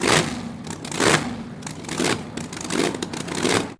Boat Race Single Boat, Rev Engine